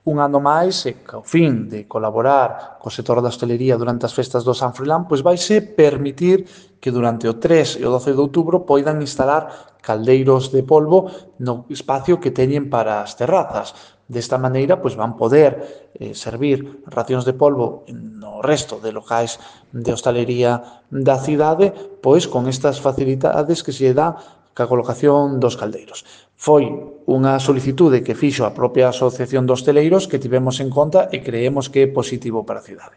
Audio El Teniente de Alcalde del Ayuntamiento de Lugo, Rubén Arroxo, sobre los calderos del San Froilán | Descargar mp3